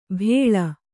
♪ bhēḷa